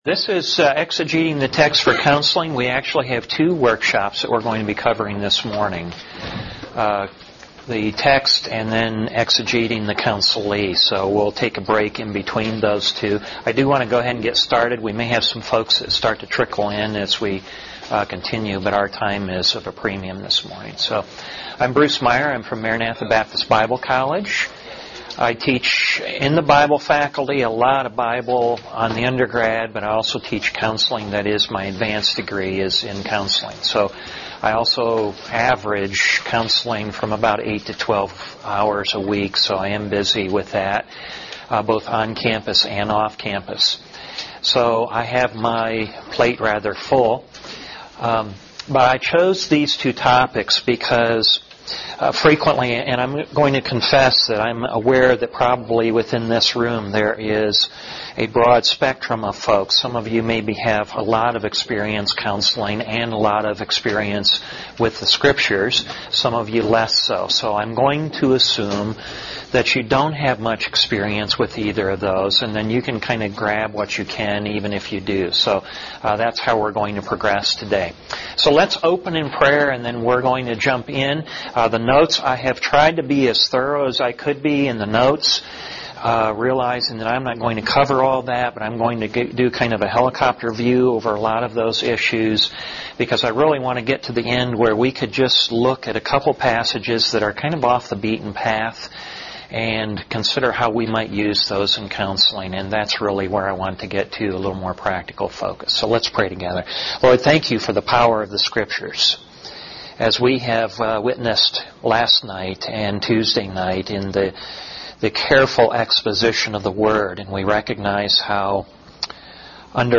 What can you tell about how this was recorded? FBFI National Fellowship: Workshop